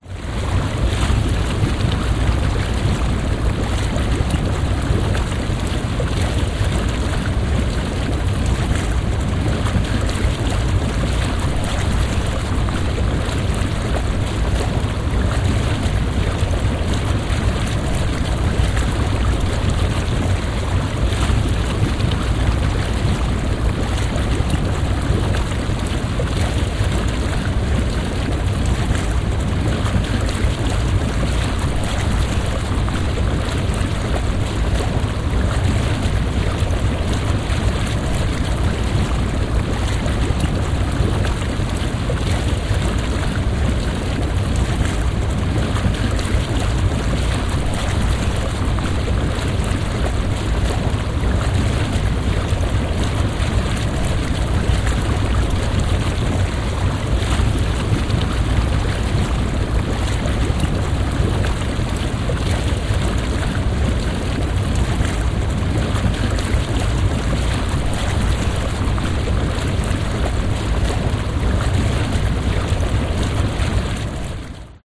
Звуки спа, атмосфера
Релакс в спа с джакузи, пузыри вокруг, вид из-под воды, бинауральный звук